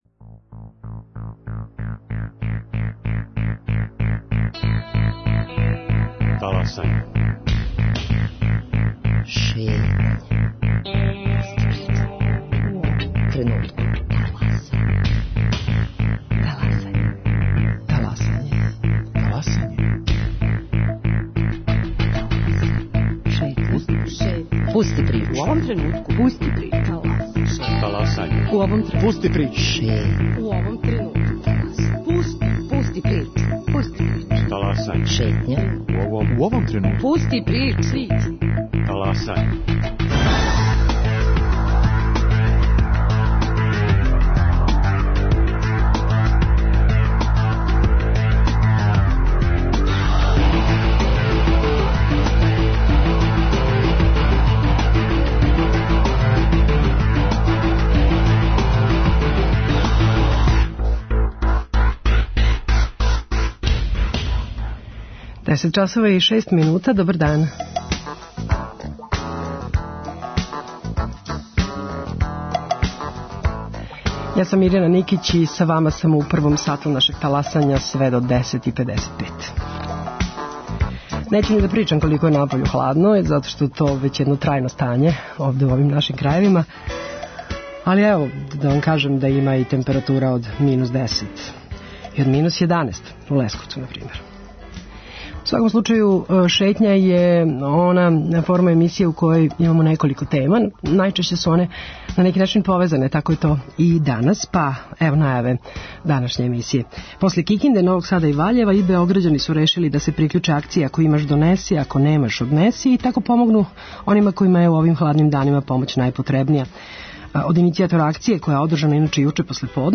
За Шетњу о томе говоре активисти поменутог Друштва.